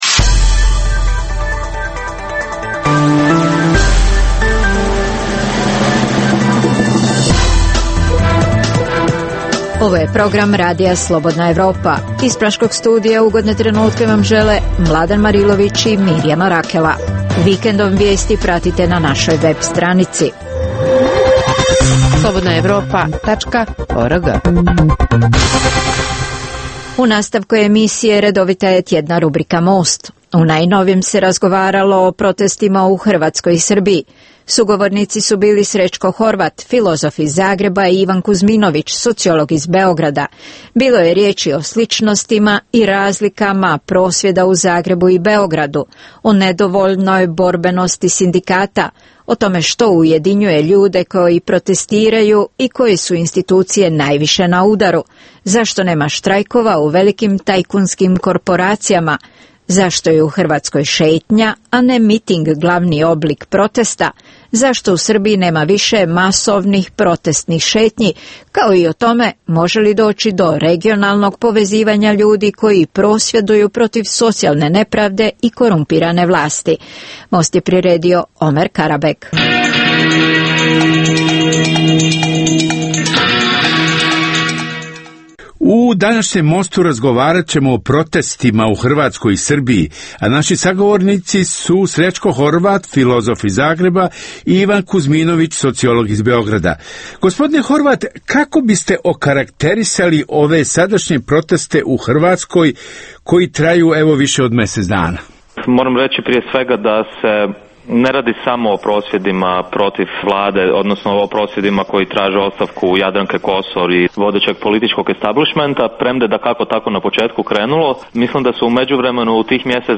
Emisija o dešavanjima u regionu (BiH, Srbija, Kosovo, Crna Gora, Hrvatska) i svijetu.